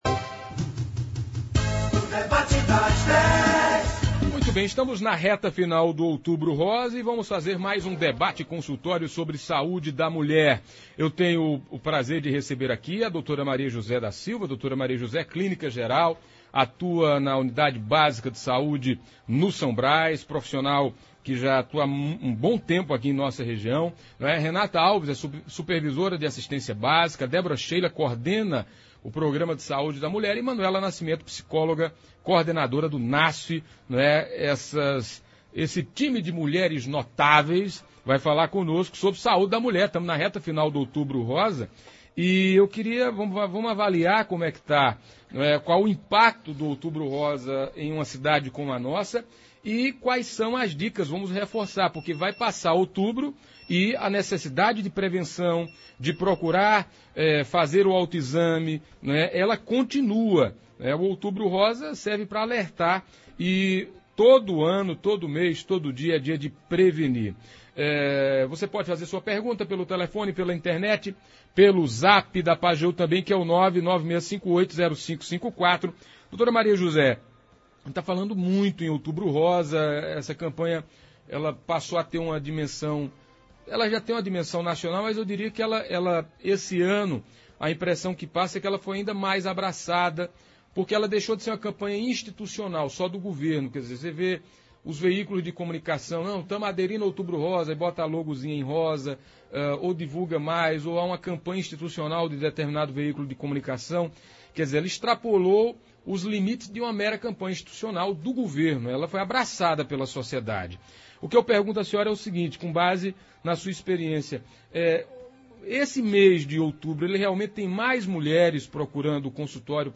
A Campanha deste ano esta em sua reta final e hoje foi mais uma vez foi tema no Debate das Dez da Rádio Pajeú. Nos estúdios